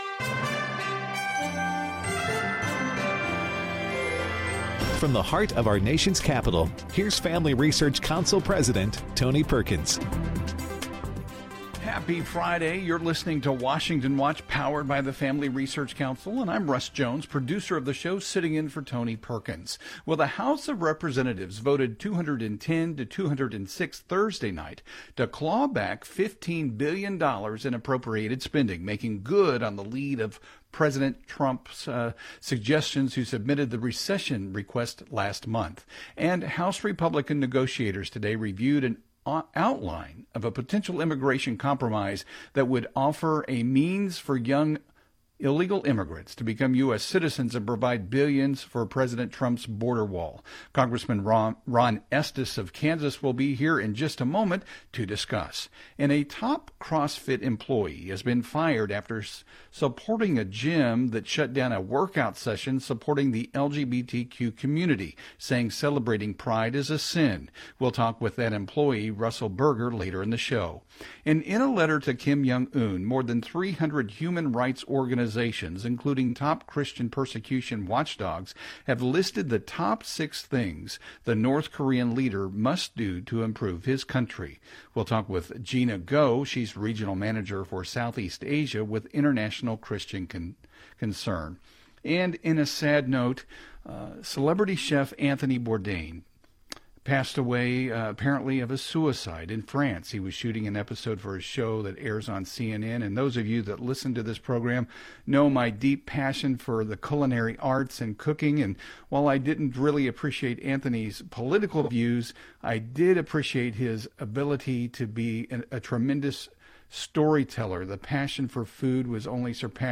House Homeland Security Committee member Rep. Ron Estes (R-Kan.) joins our guest host with the latest from Capitol Hill.